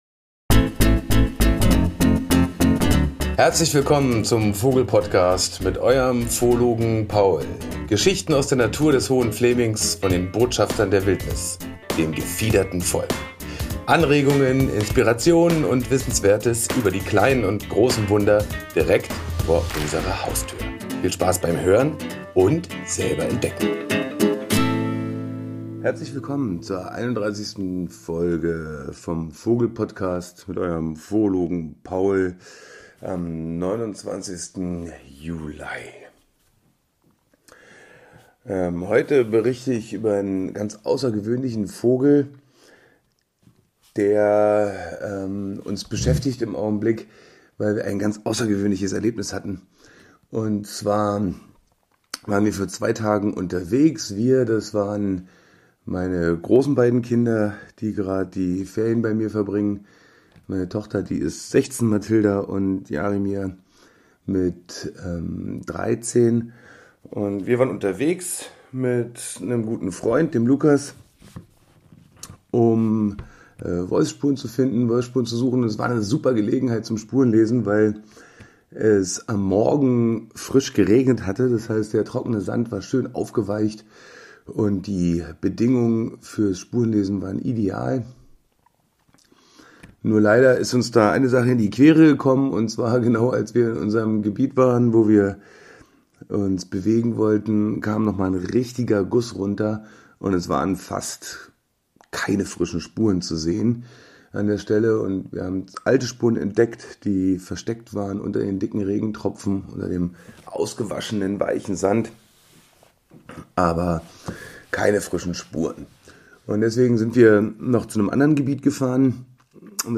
Ein Spaziergang im Wald mit vielen Überraschungen, seltenen Entdeckungen und einigen Aha- Effekten... Manche Dinge müssen wir gezeigt bekommen oder darauf hingewiesen werden, um sie in der freien Natur überhaupt zu entdecken.